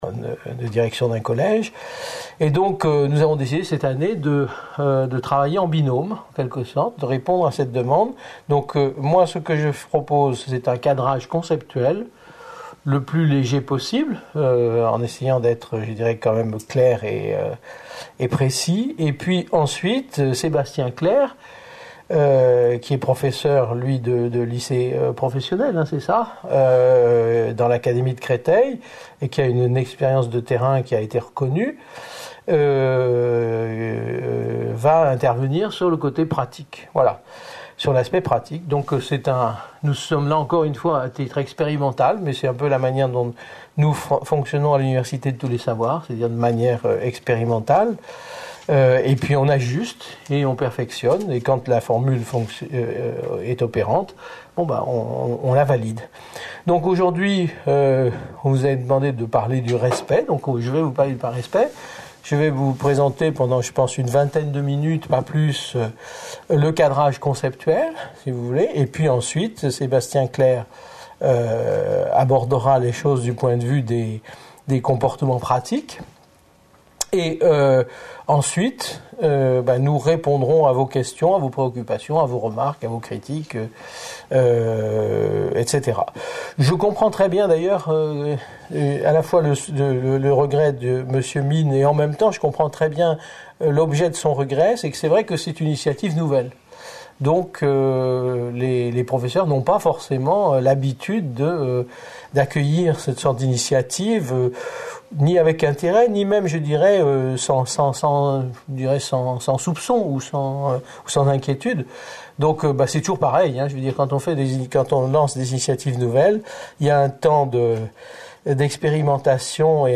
Lycée Hélène Boucher (75020 Paris)